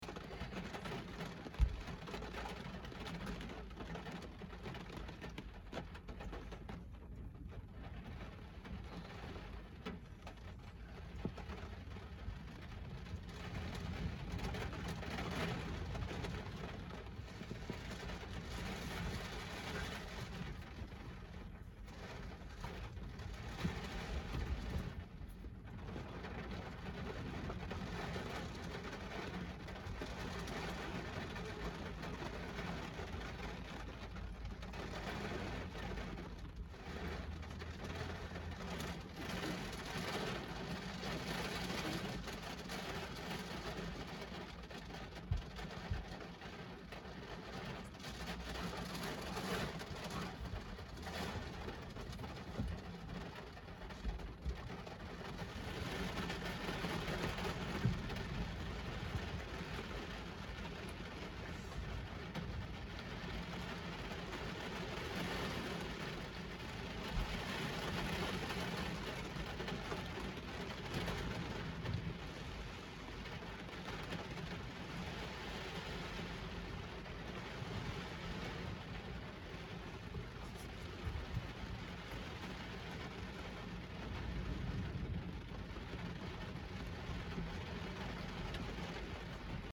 Стук дождевых капель по машине